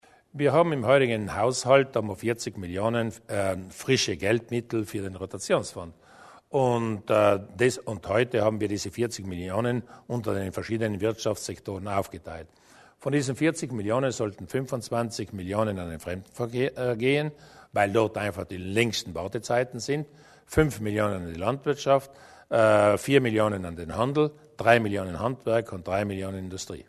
Landeshauptmann Durnwalder über die Verteilung der Gelder aus dem Rotationsfonds